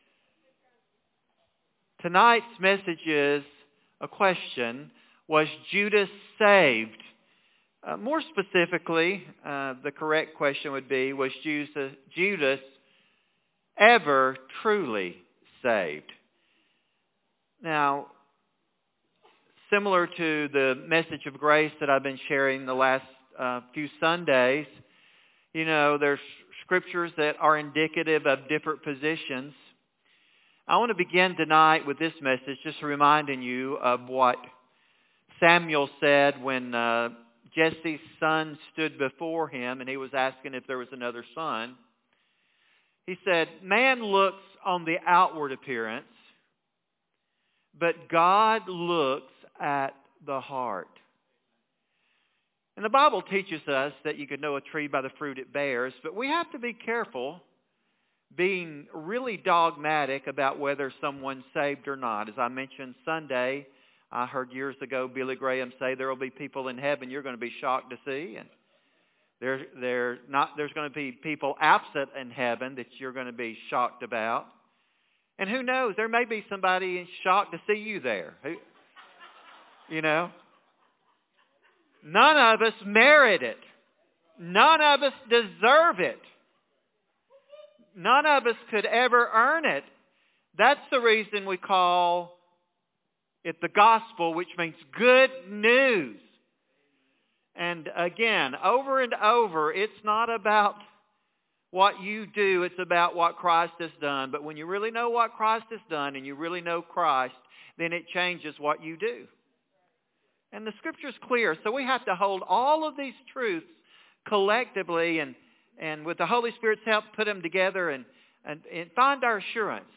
A compelling message